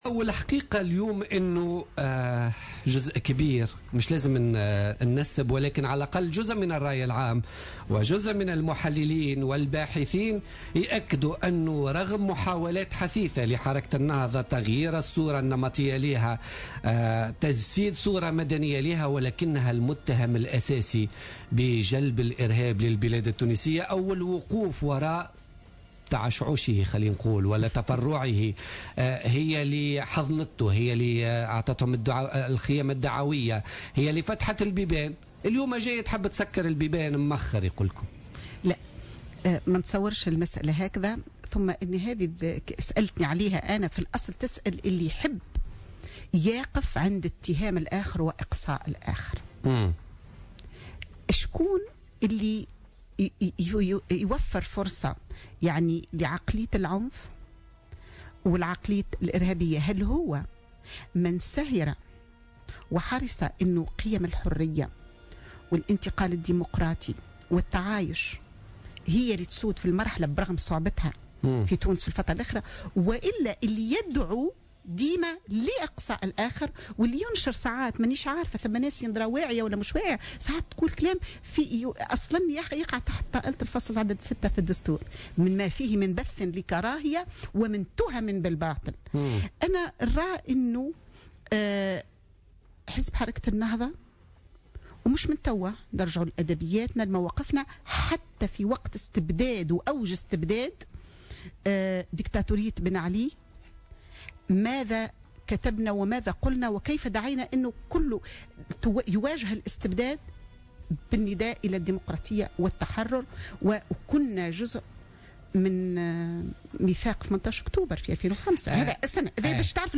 قالت النائب والقيادية في حركة النهضة محرزية العبيدي ضيفة برنامج بوليتكا لليوم الاثنين 14 ديسمبر 2015 إن نظام بن علي الديكتاتوري هو الذي أنتج الإرهاب في تونس.